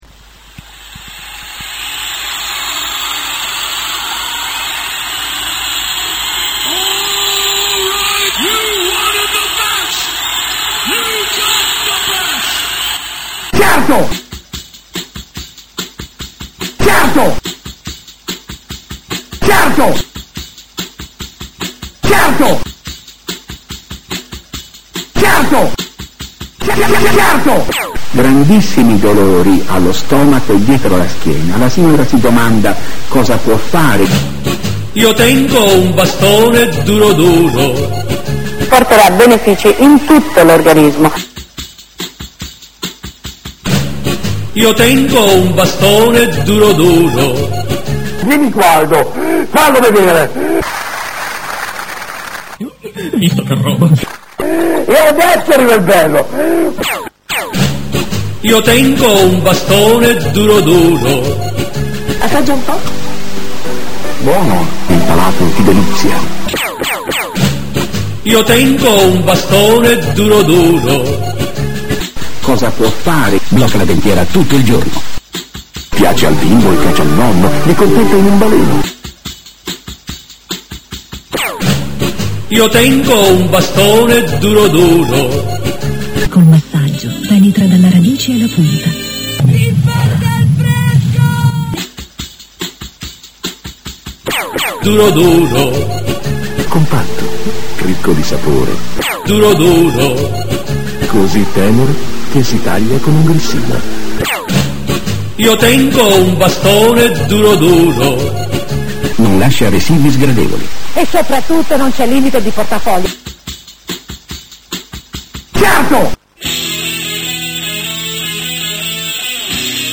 Un geniale mix di varie pubblicita'
dal risultato esilarante